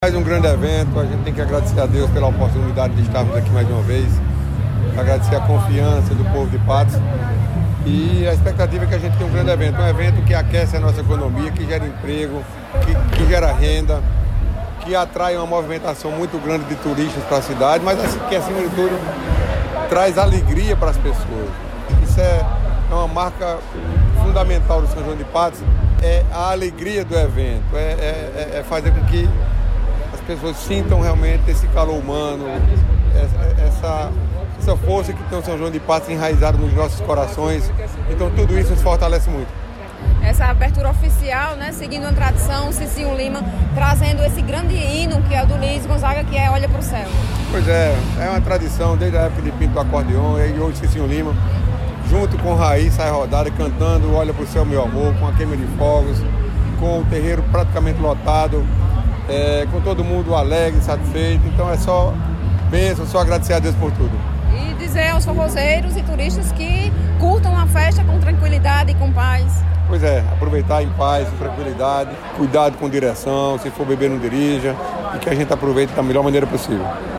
Ouça a avaliação do prefeito Nabor Wanderley: